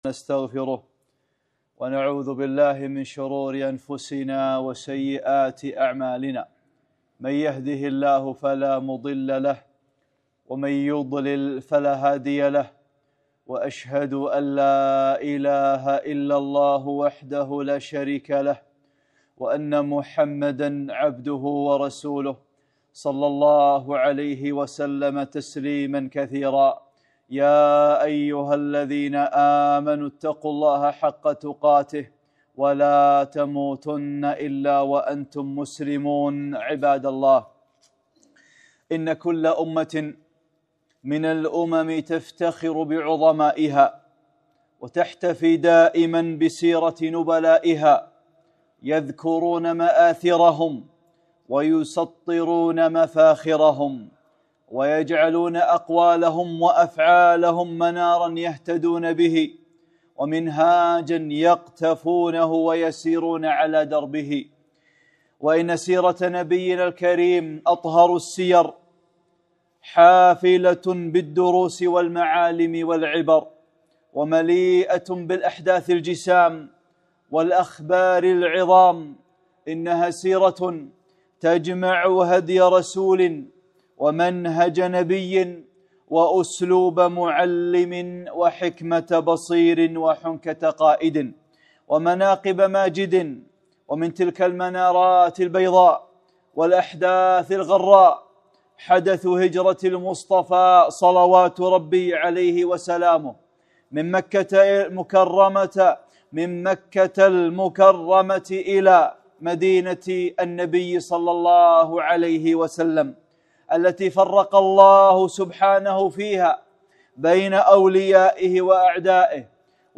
خطبة - دروس وعبر من الهجرة النبوية